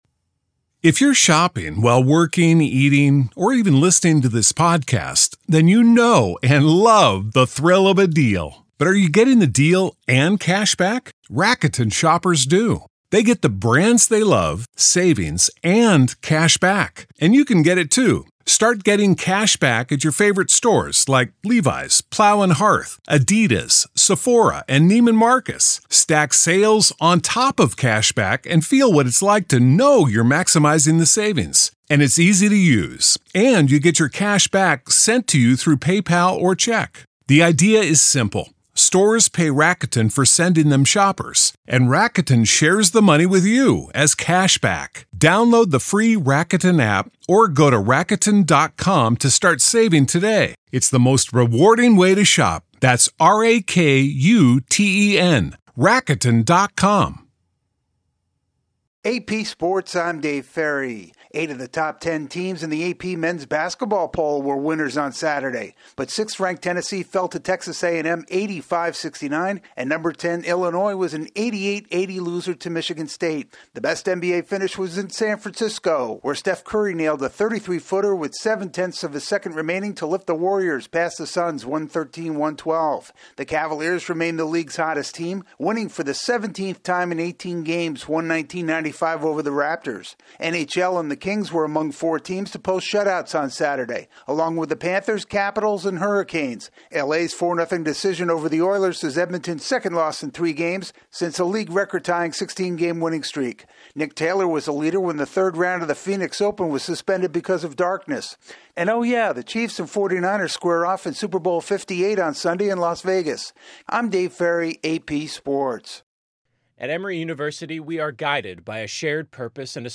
The latest in sports